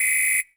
6WHISTLE L22.wav